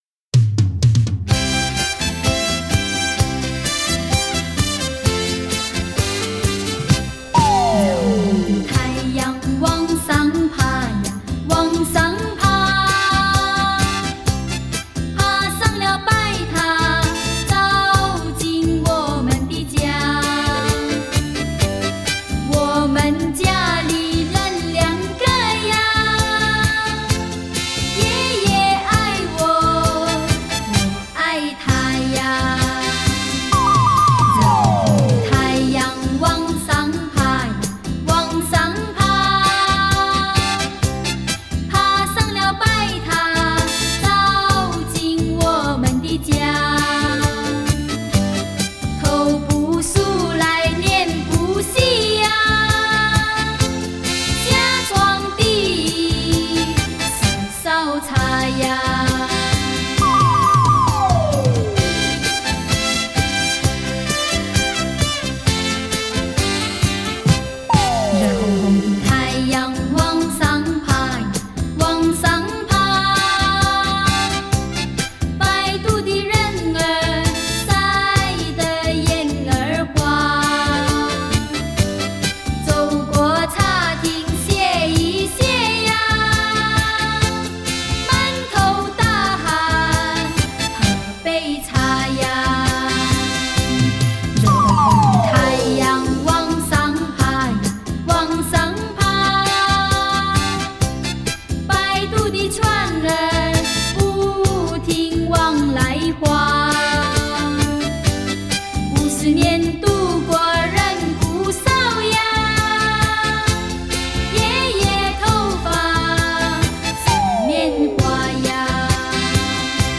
出版载体：黑胶唱片